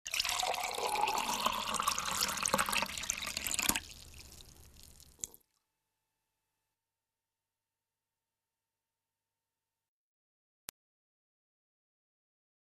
LLENANDO UN VASO DE AGUALLENANDO UN VASO DE AGUA
EFECTO DE SONIDO DE AMBIENTE de LLENANDO UN VASO DE AGUALLENANDO UN VASO DE AGUA
llenando_un_vaso_de_aguallenando_un_vaso_de_agua.mp3